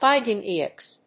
発音記号
• / hὰɪdʒíːnɪks(米国英語)